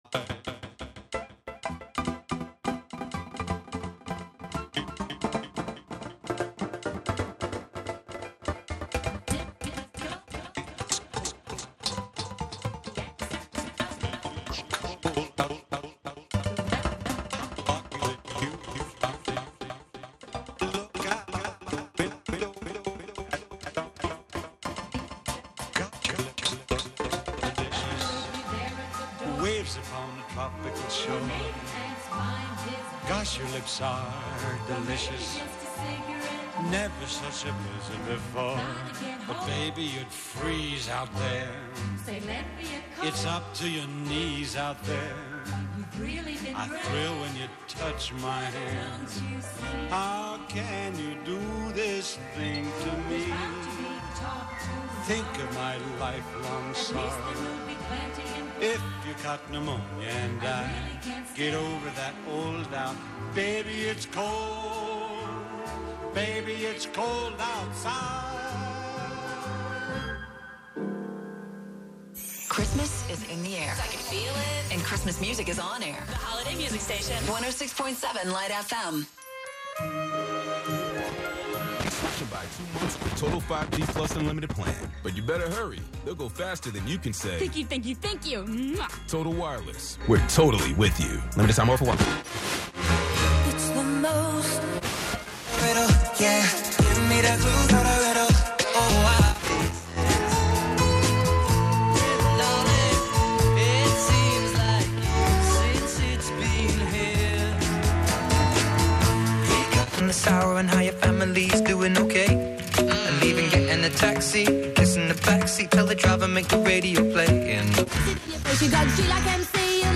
11am Live from Brooklyn, New York